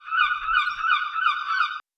Seagull 002.wav